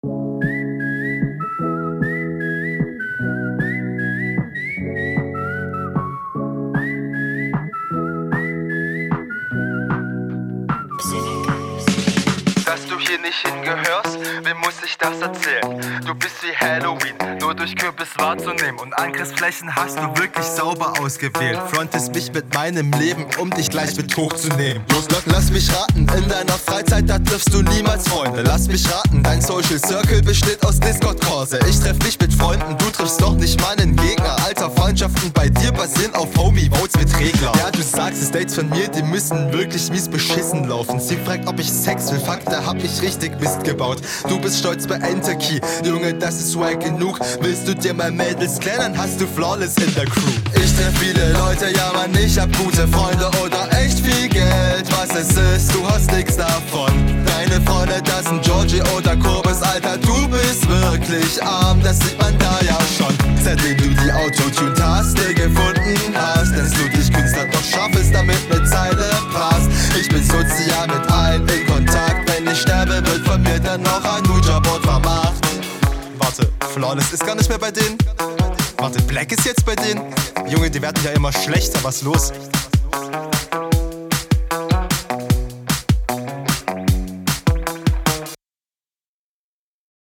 Flow ist besser als in deiner HR, wohl aufgrund der niedrigeren BPM.